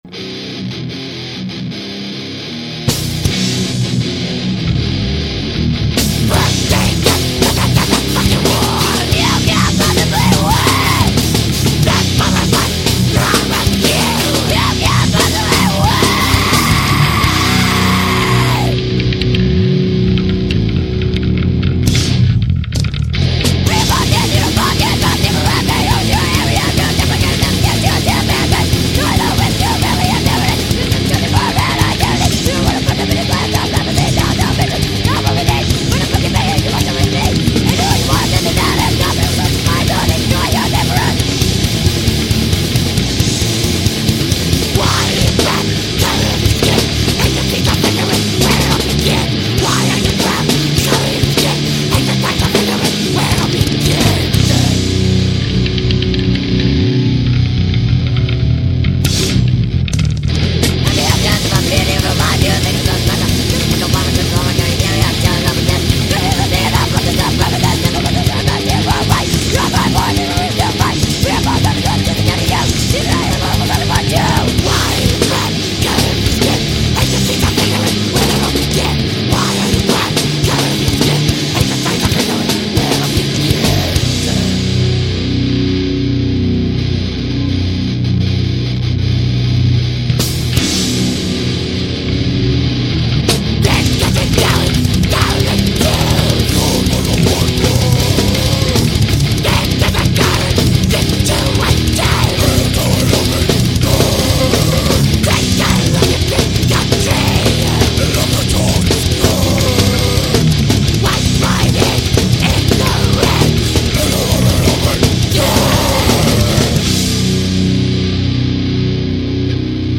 creating a totally intense and orginal sound.